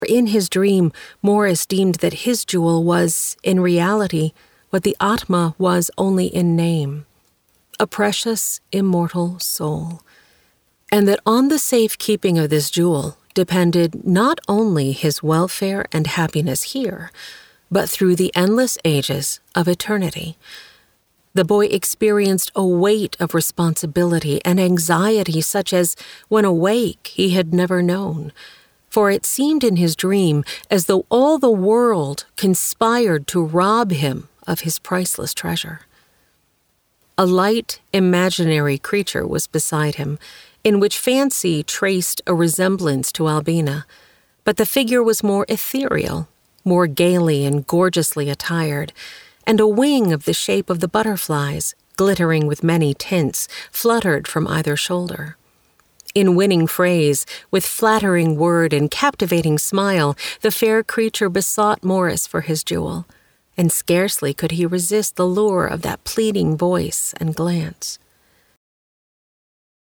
Audiobook: The Jewel - MP3 download
This is an audiobook, not a Lamplighter Theatre drama.